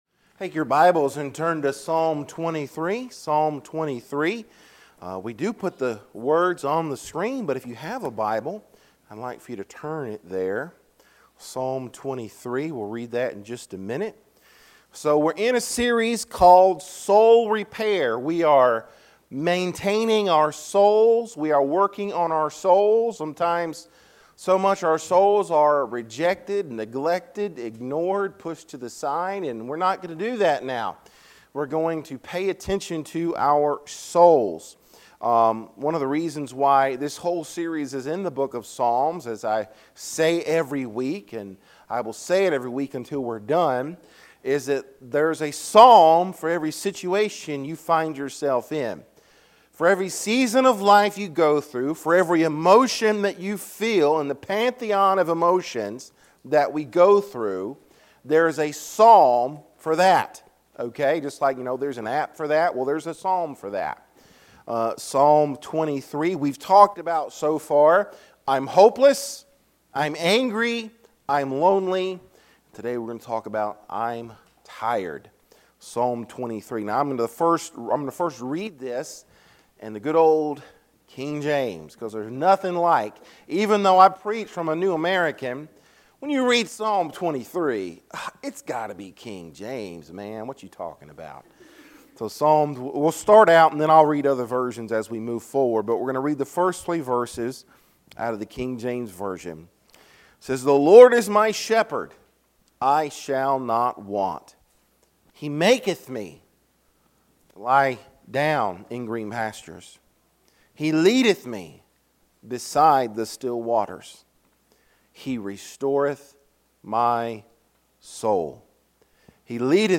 Sermons | Rocky Point Baptist Church